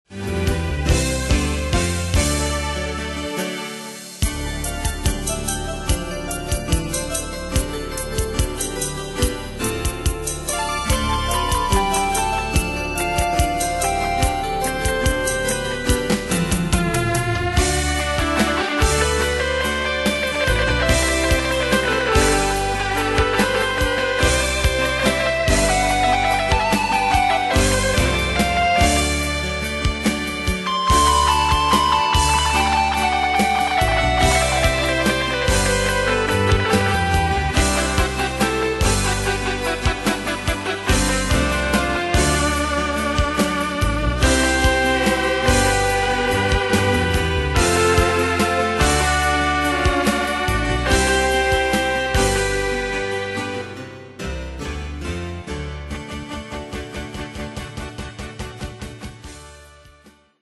Style: Rock Ane/Year: 1986 Tempo: 140 Durée/Time: 5.24
Danse/Dance: Rock Cat Id.
Pro Backing Tracks